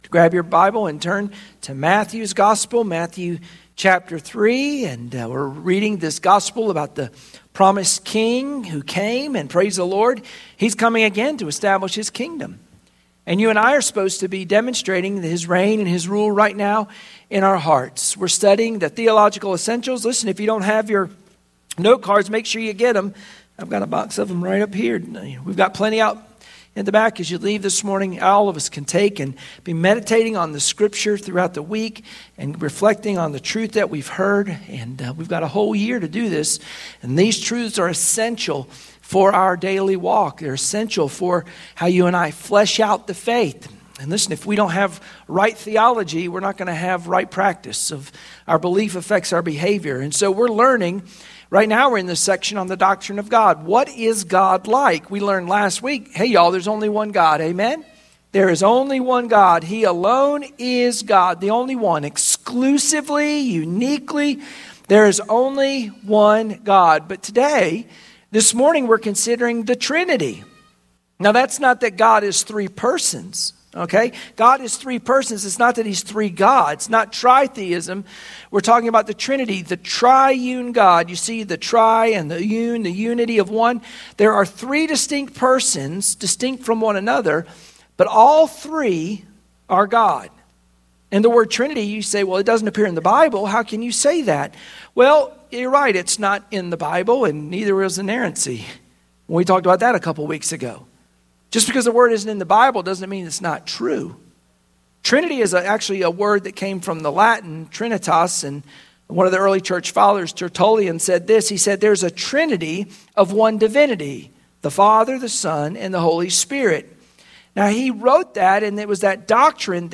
Sunday Morning Worship Passage: Matthew 3:13-17 Service Type: Sunday Morning Worship Share this